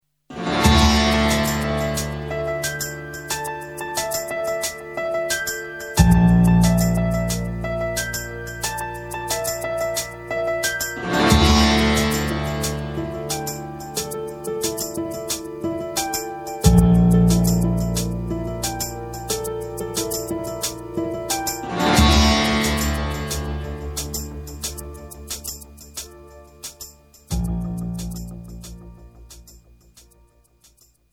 Sinister sitars .